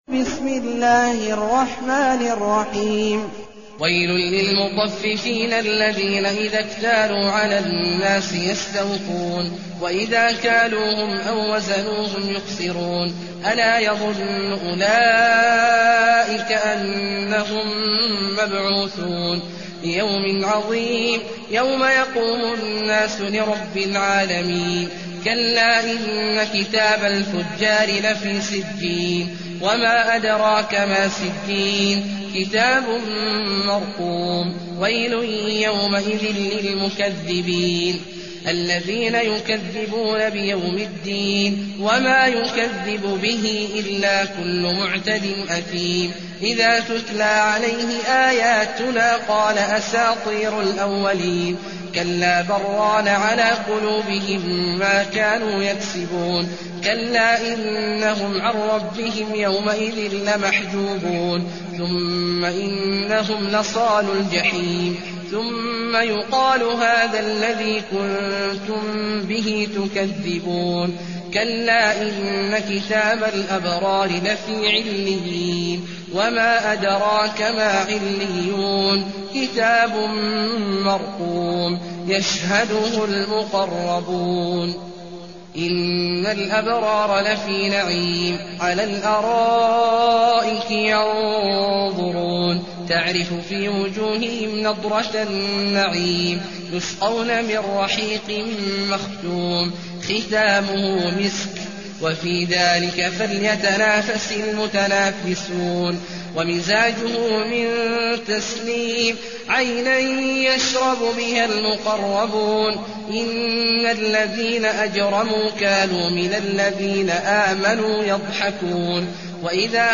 المكان: المسجد الحرام الشيخ: عبد الله عواد الجهني عبد الله عواد الجهني المطففين